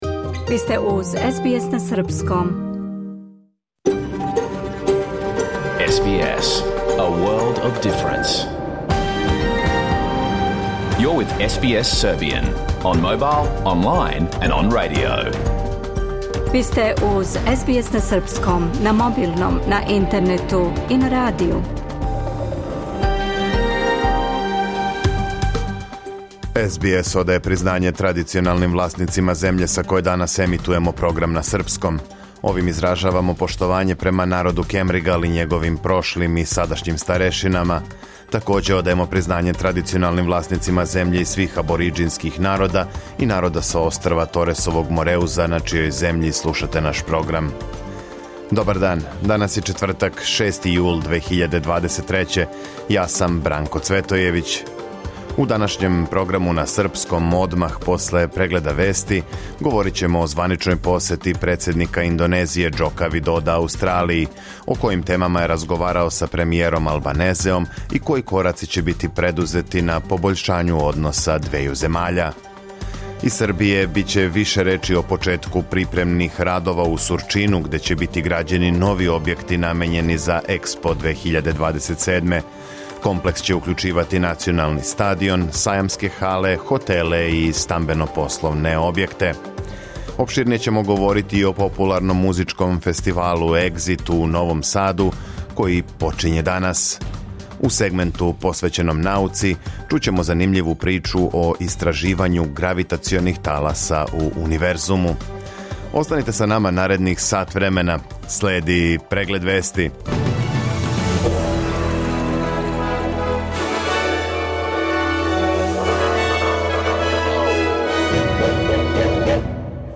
Програм емитован уживо 6. јула 2023. године
Уколико сте пропустили данашњу емисију, можете је послушати у целини као подкаст, без реклама.